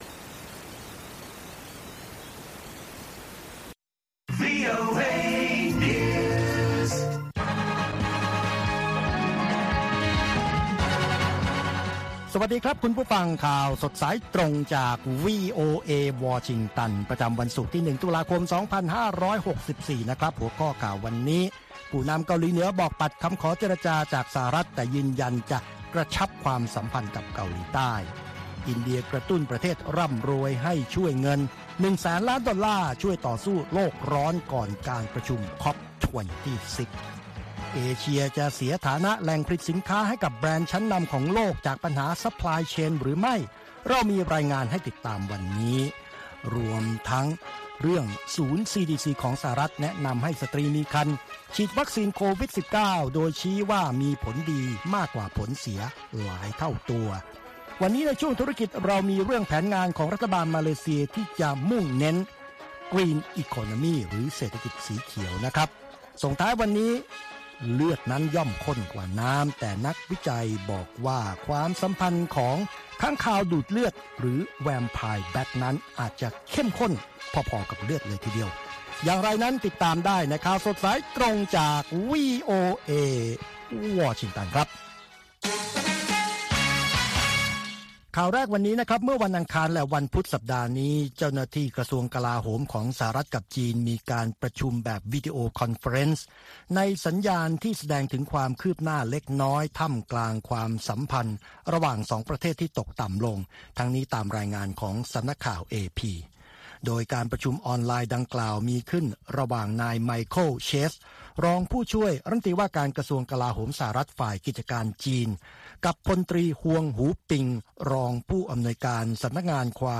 ข่าวสดสายตรงจากวีโอเอ ภาคภาษาไทย ประจำวันศุกร์ที่ 1 ตุลาคม 2564 ตามเวลาประเทศไทย